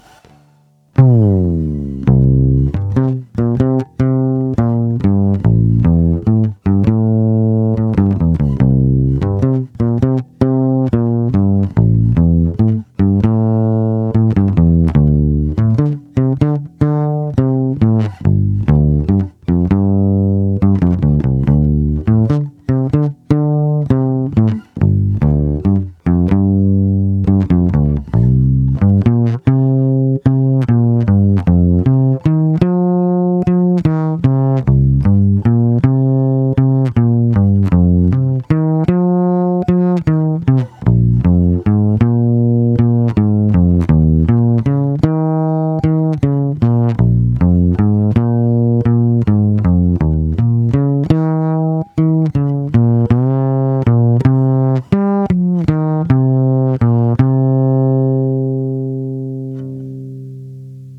Zvuk je opravdu hutný a i díky piezo snímači dostává akustický, až kontrabasový nádech. Ostatně posuďte sami z nahrávek, které jsou pořízené přes zvukovku do PC, bez úprav.
Piezo snímač
Máš moc velkou citlivost na vstupu, takže je signál ořezaný, zkreslený a proto to ve zvuku chrčí.